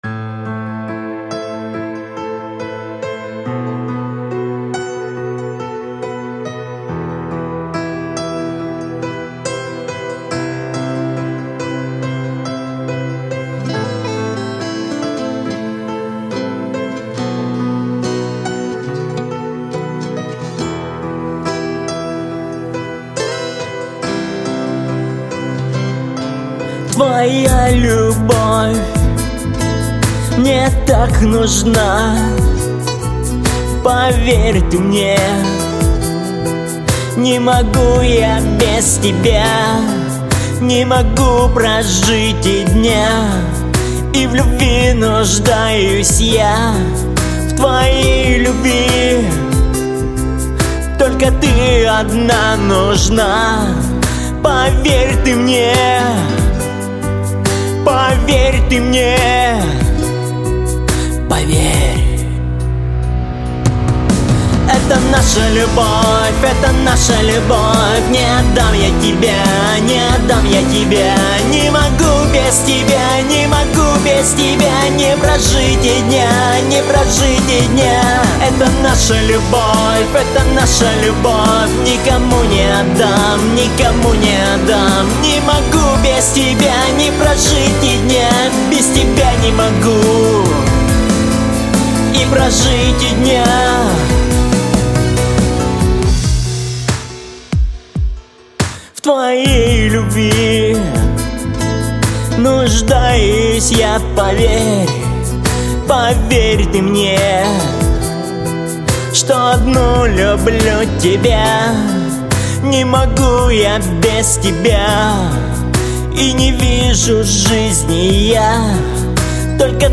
Поп певец
Баритон